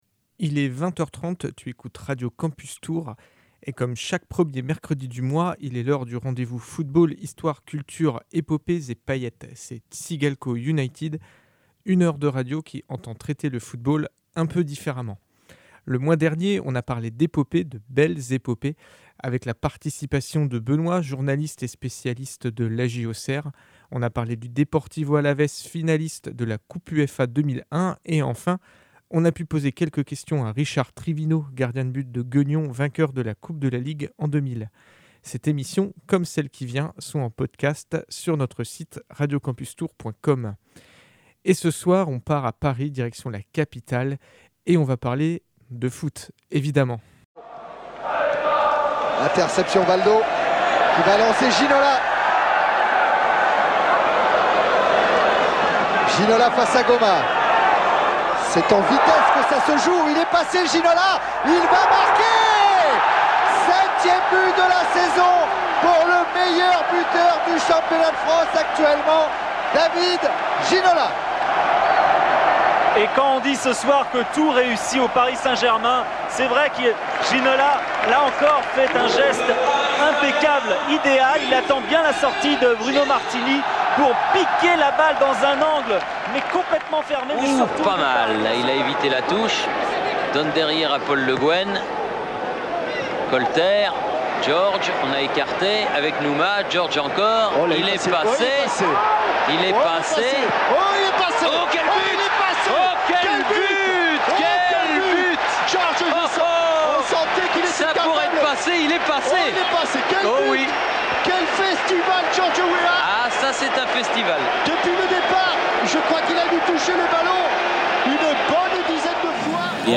Pauses musicales